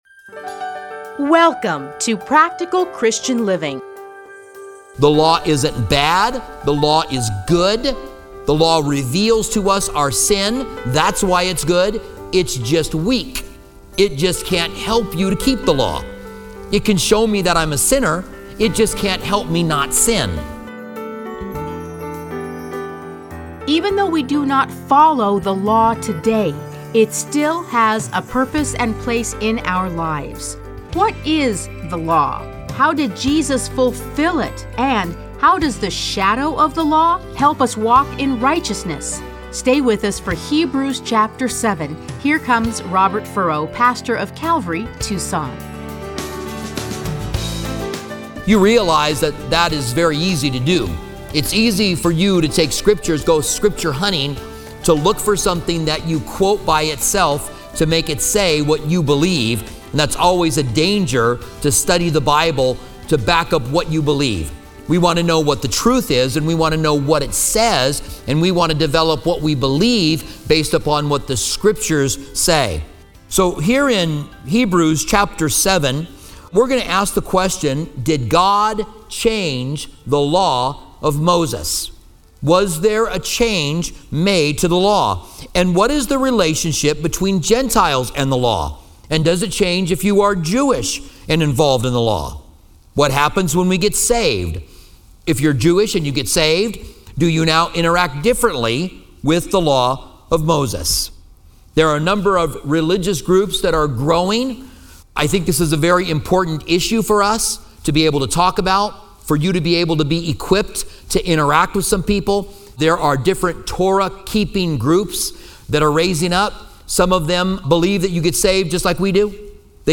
Listen to a teaching from Hebrews 7:1-28.